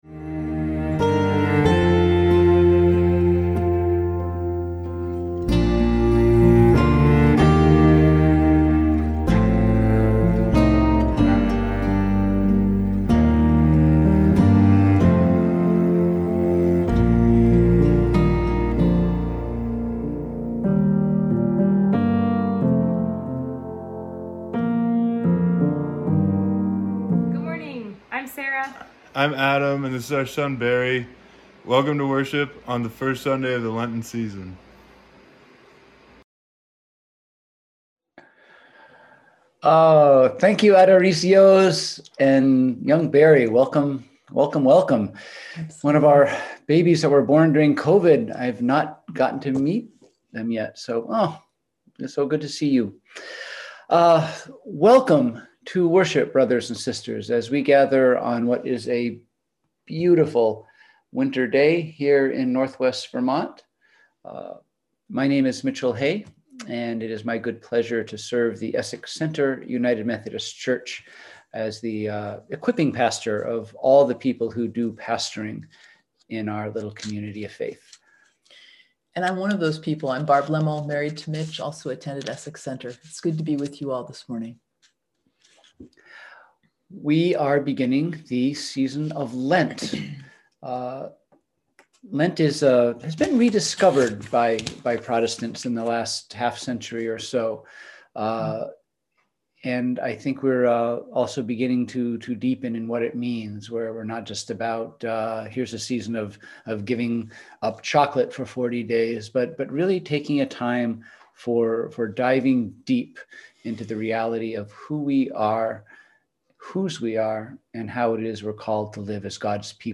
We held virtual worship on Sunday, February 21, 2021 at 10:00AM!